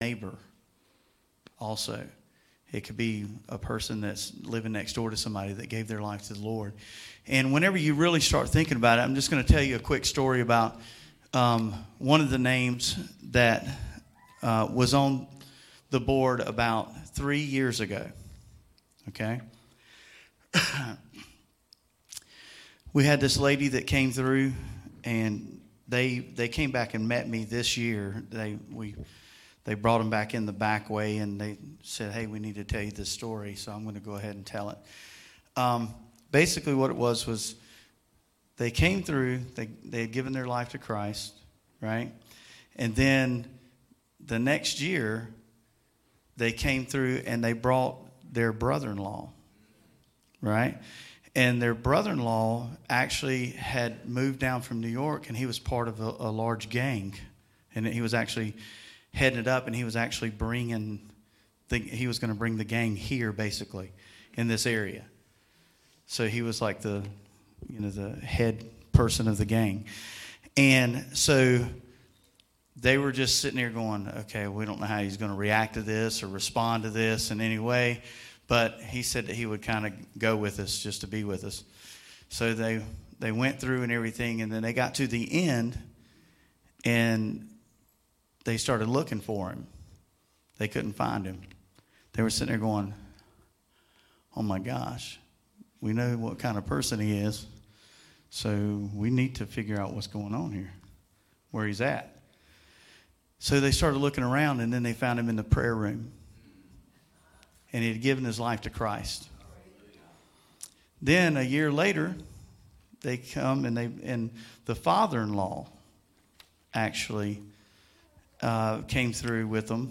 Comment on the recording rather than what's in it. Helloween Celebration Service 2018